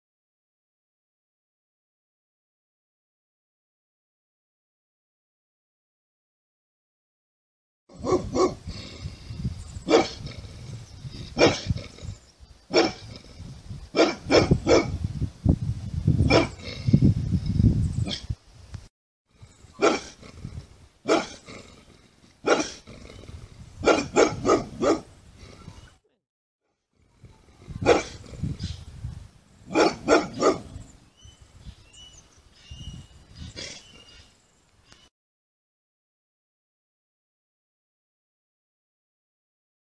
Dog Barking Animal Sounds Bulldog Botón de Sonido